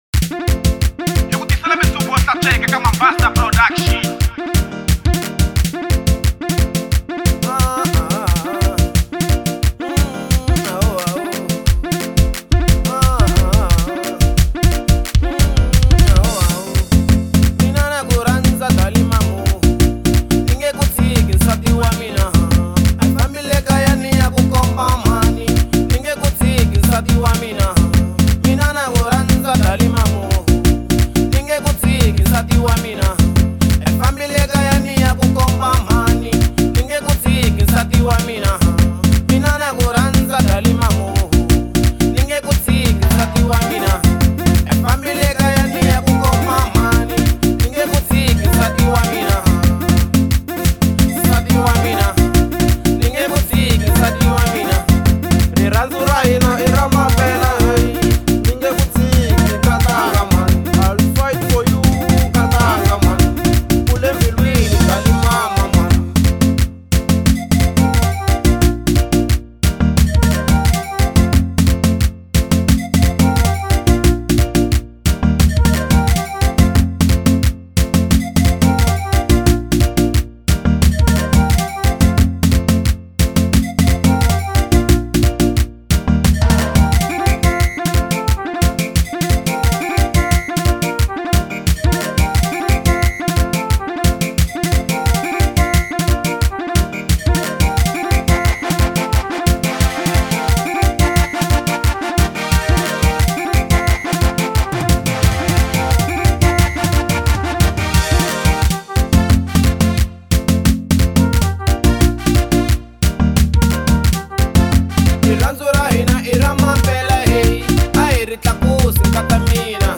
Genre : Xitsonga